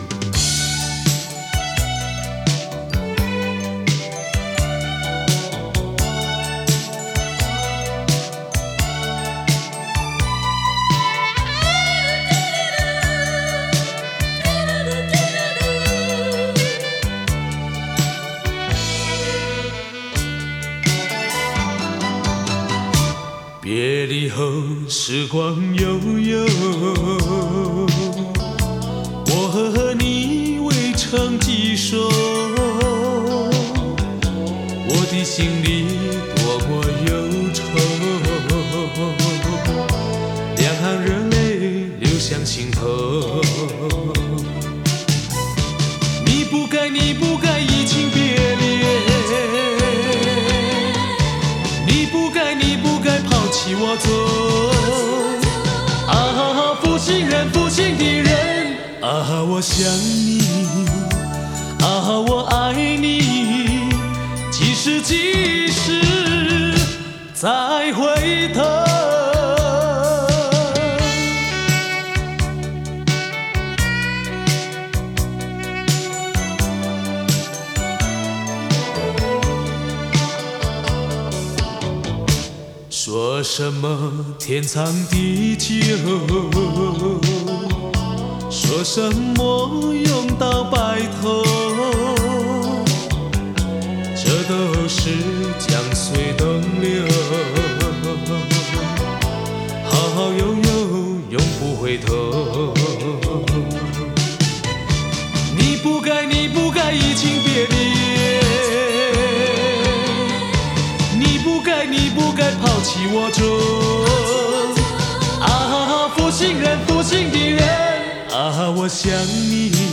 整张CD应该是联唱，好象3首曲子为一组。